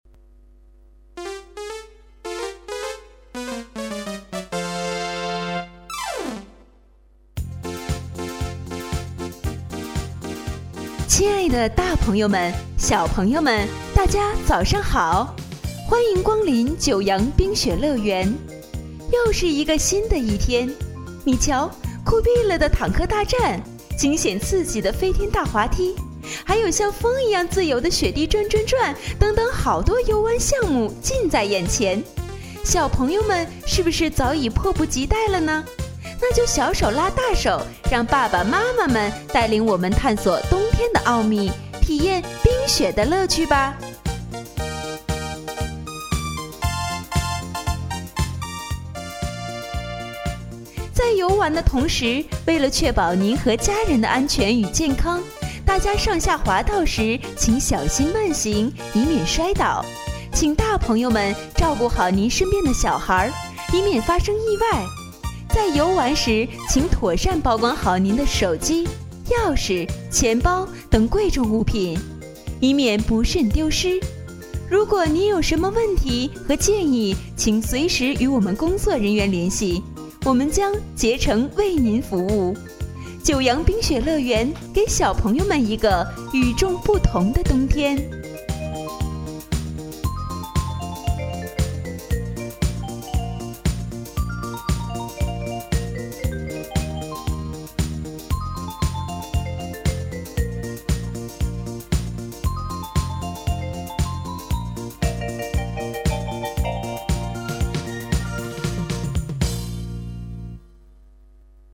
移动水上乐园之九洋冰雪乐园广播
移动水上乐园之九洋冰雪乐园开园词活泼版
移动水上乐园开园词活泼版.mp3